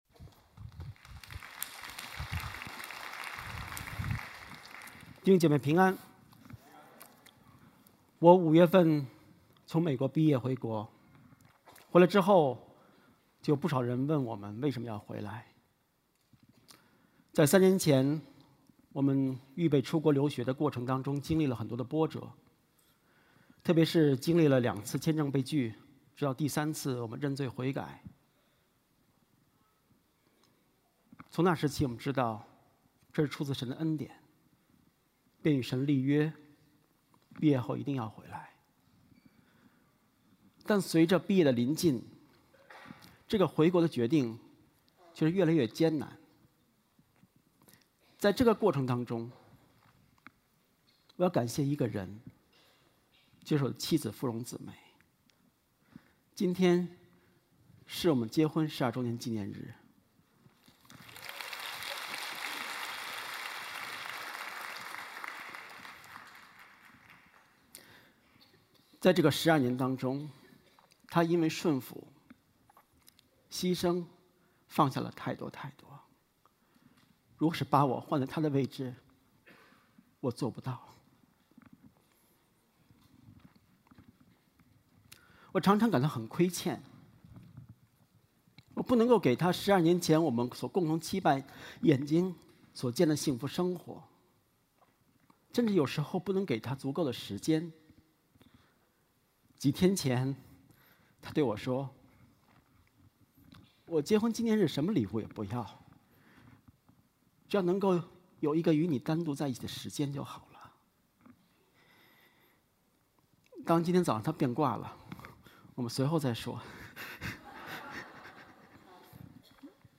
IMS Sermons